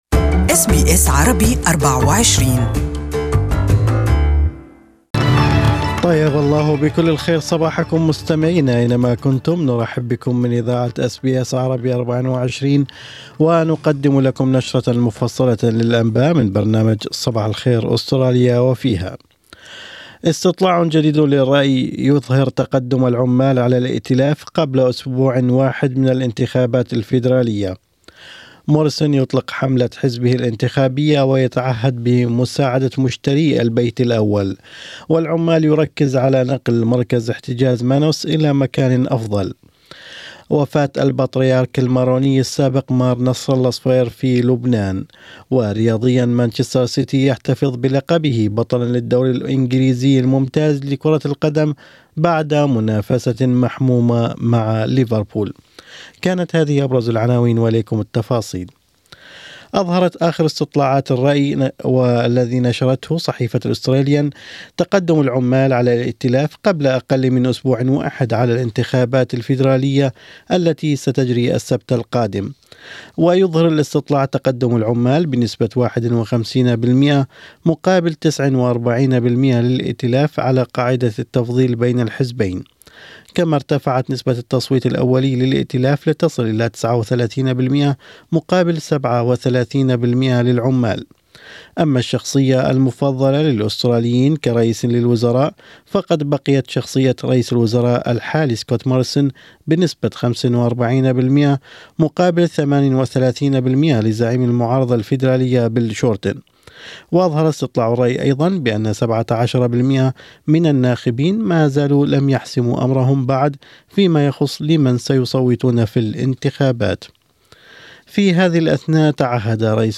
Morning News Bulletin (13/5/19)